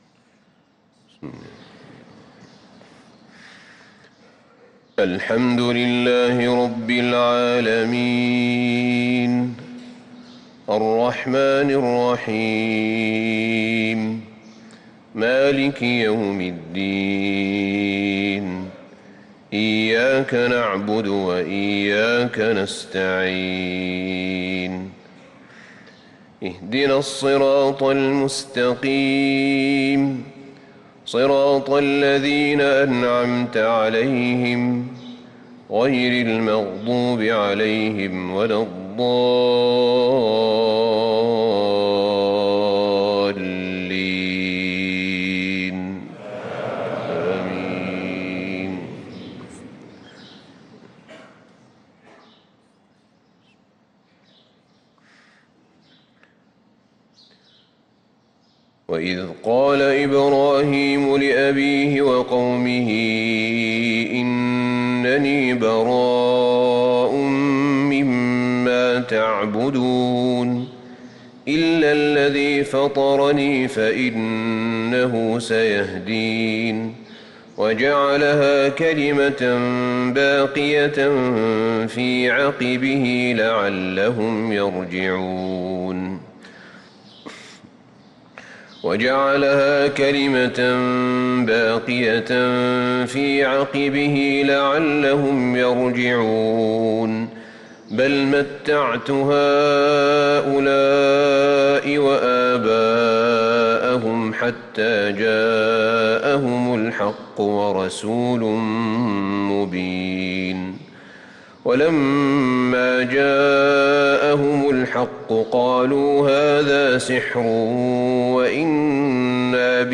صلاة الفجر للقارئ أحمد بن طالب حميد 20 ربيع الآخر 1445 هـ
تِلَاوَات الْحَرَمَيْن .